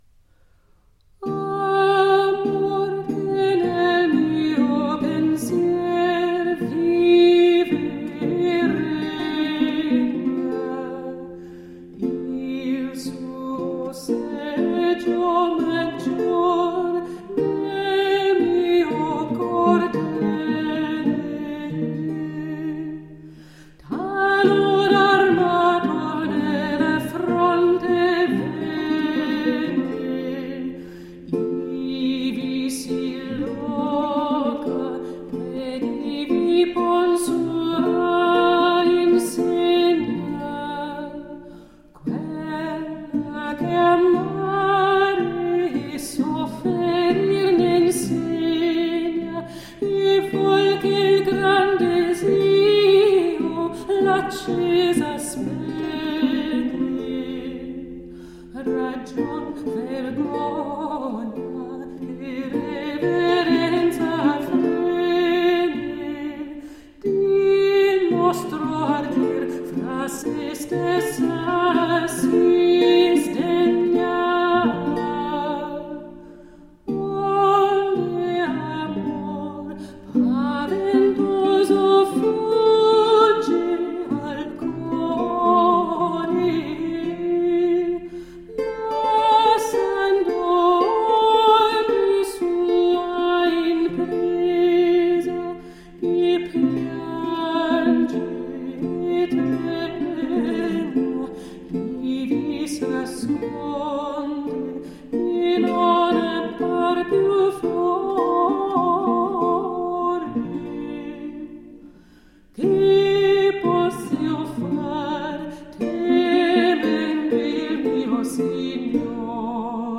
Renaissance songs.